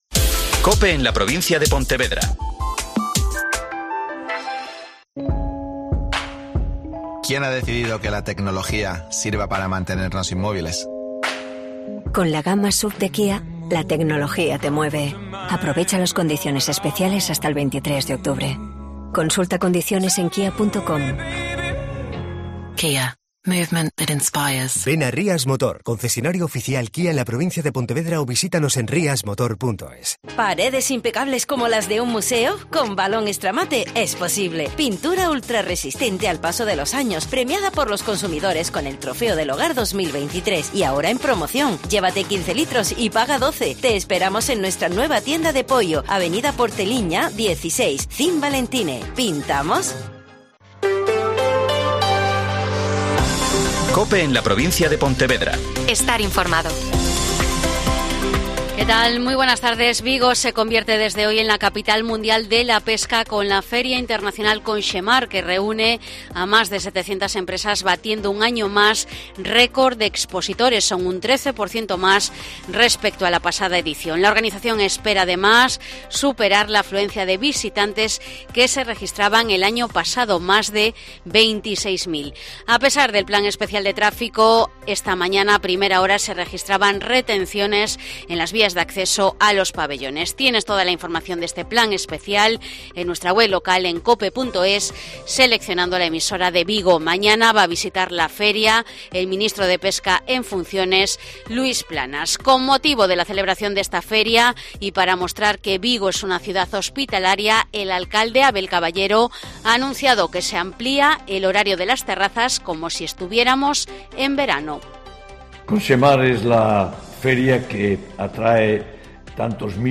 Mediodía COPE Pontevedra y COPE Ría de Arosa (Informativo 14:20h)